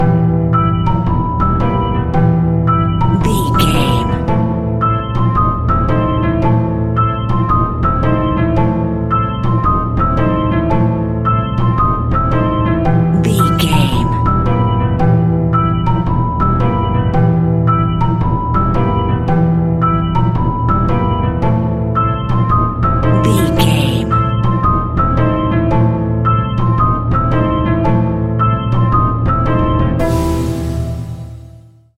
Aeolian/Minor
scary
ominous
dark
suspense
haunting
eerie
piano
strings
electric piano
organ
electric organ
percussion
drums
creepy
spooky
instrumentals
horror music